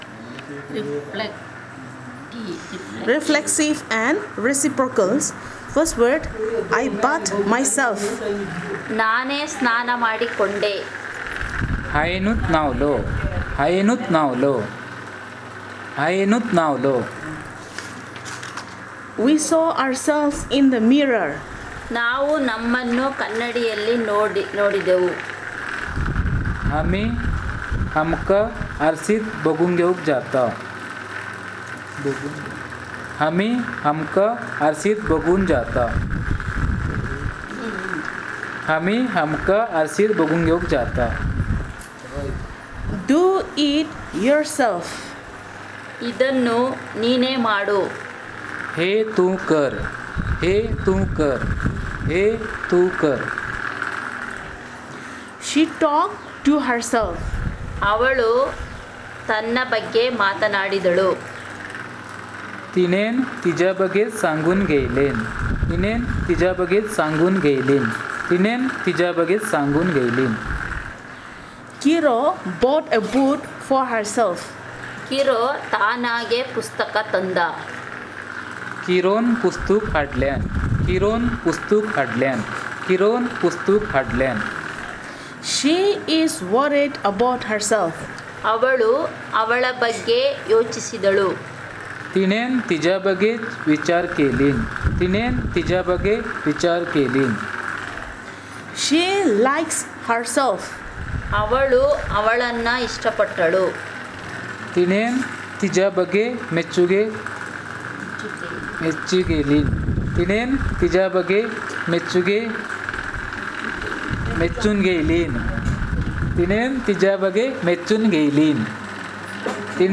Elicitation of sentences about reflexive and reciprocal